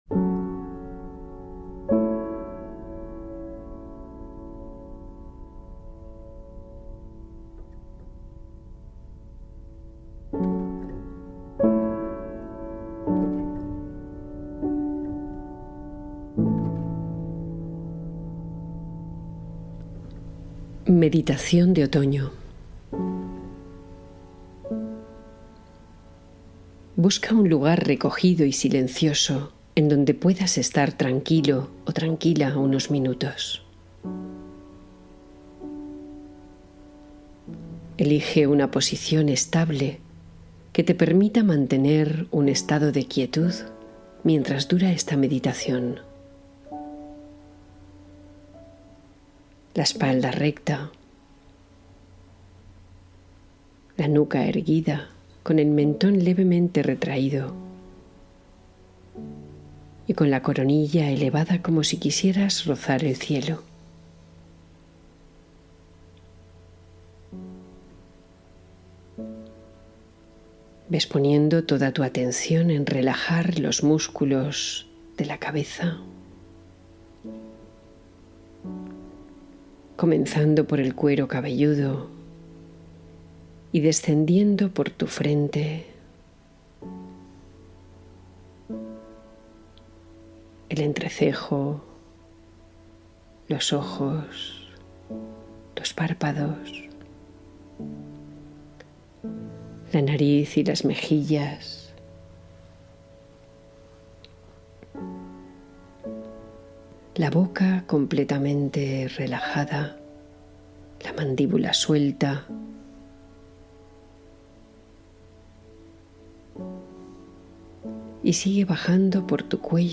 Meditación guiada de otoño: renovación y serenidad interna